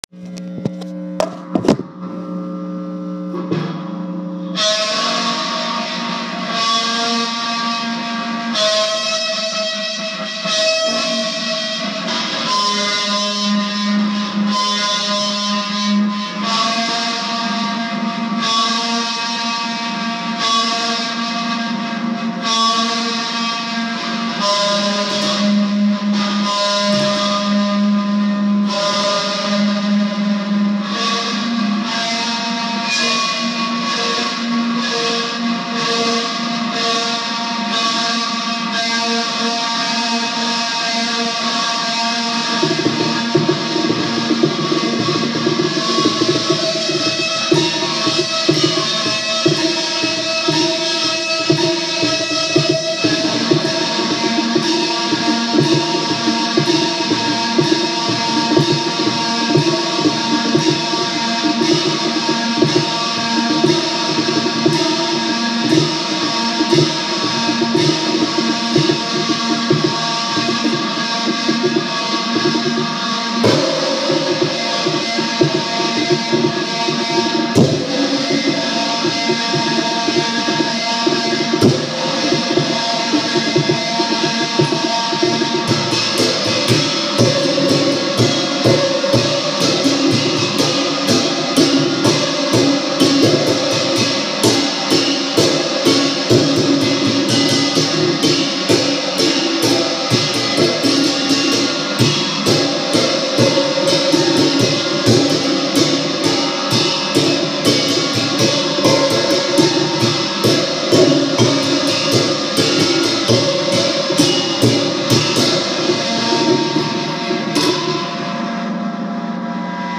Musical Metal
playing-a-metal-sculpture-i-made.m4a